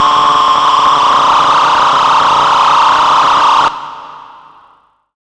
OLDRAVE 6 -L.wav